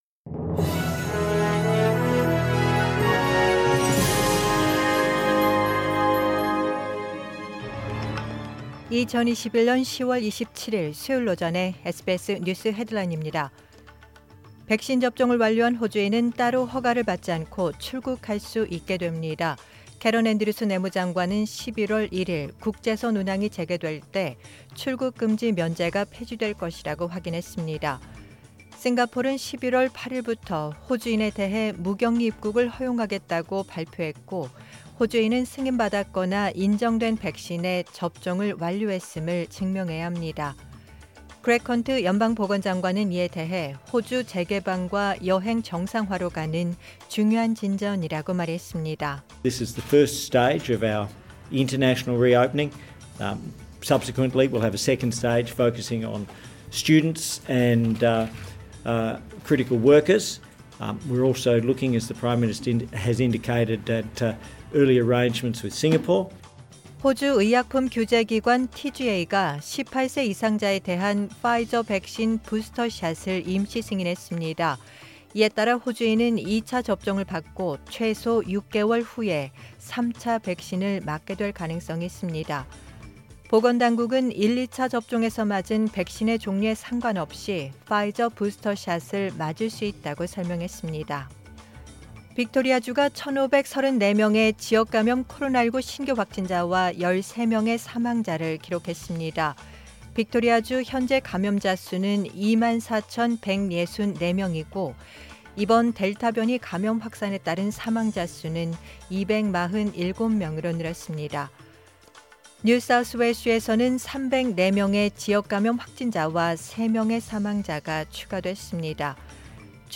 2021년 10월 27일 수요일 오전의 SBS 뉴스 헤드라인입니다.